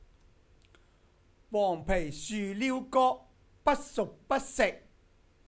57黄枇树鹩哥